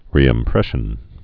(rēĭm-prĕshən)